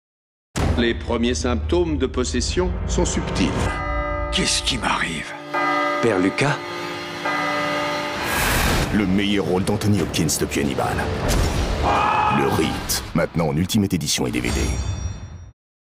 Prestation voix-off terrifiante et dynamique pour "Le Rite" par un comédien expert
Voix blockbuster horreur.
J’ai opté pour une hauteur de voix très grave, afin de rester en harmonie avec l’ambiance sombre et angoissante du film.
Pour cela, j’ai mis l’accent sur une voix terrifiante, mais également dynamique et percutante, qui évoque l’intensité d’un blockbuster.